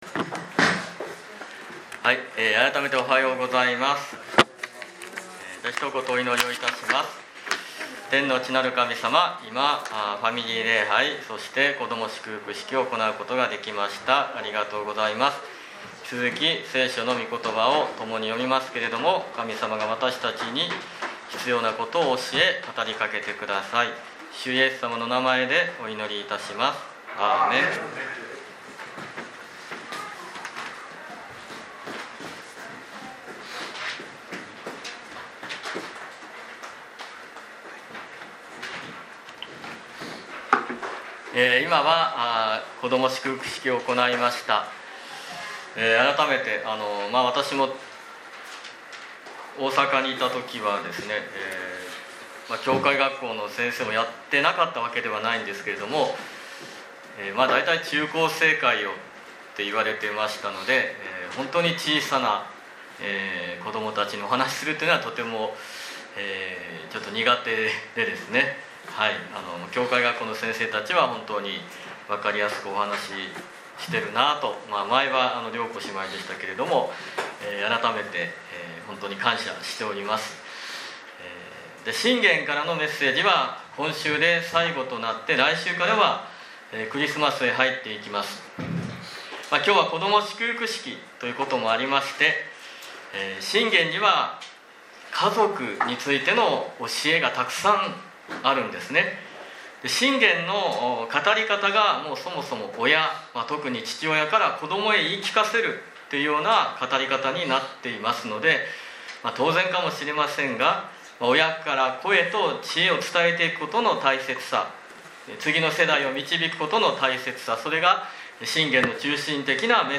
2023年11月26日礼拝メッセージ